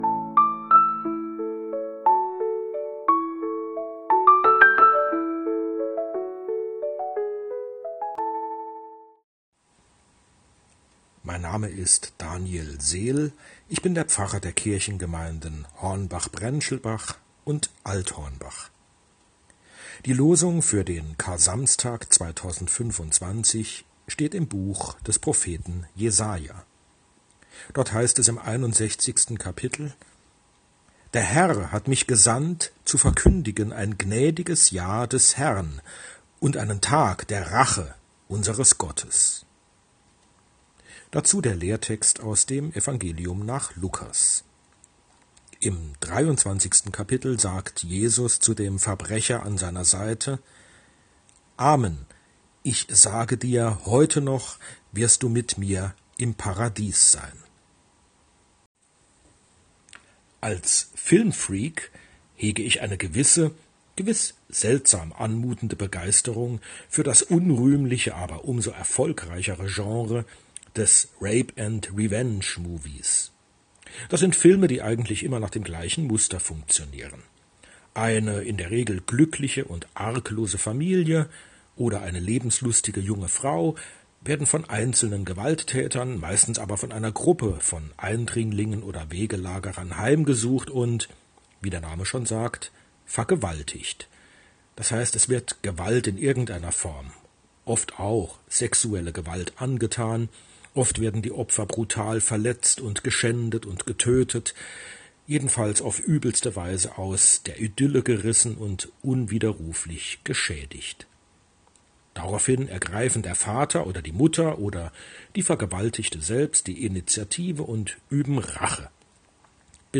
Losungsandacht für Samstag, 19.04.2025